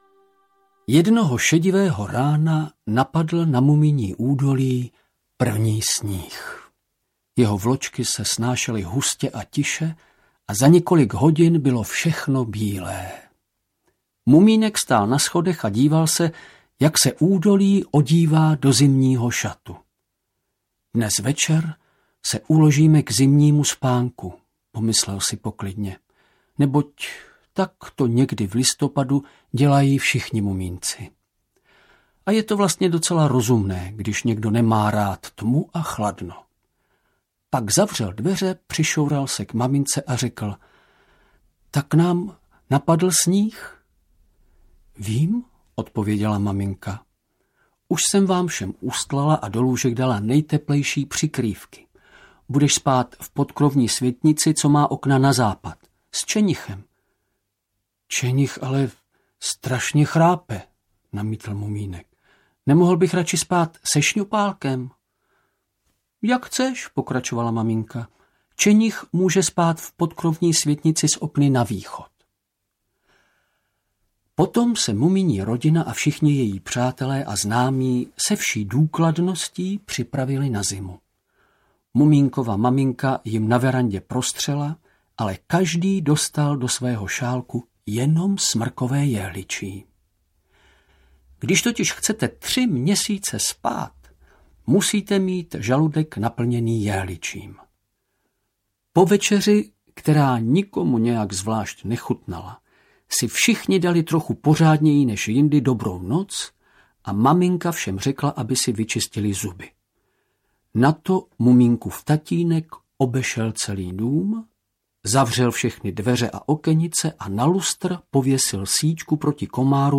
Mumínci a čarodějův klobouk audiokniha
Ukázka z knihy
• InterpretVladimír Javorský